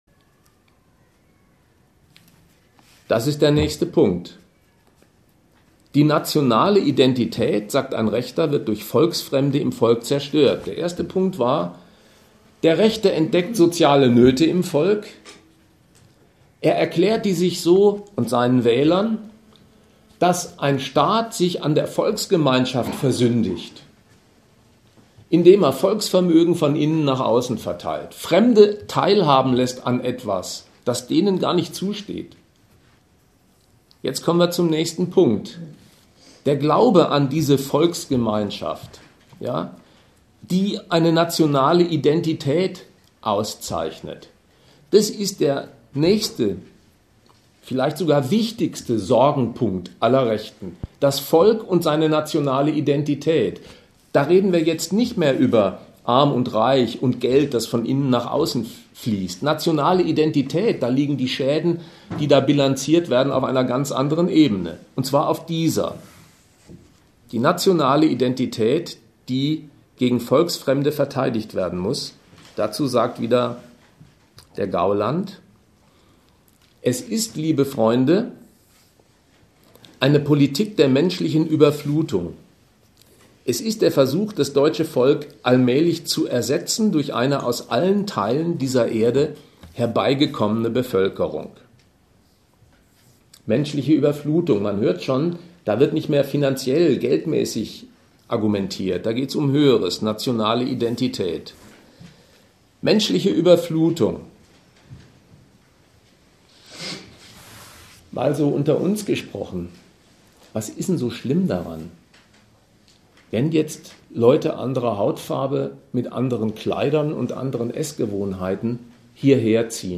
Auf diese Fragen soll der Vortrag Antworten geben. Geklärt werden soll: Was ist rechts? Und was hat der rechte Aufschwung mit der Krise zu tun?
Veranstalter: Forum Kritik Regensburg.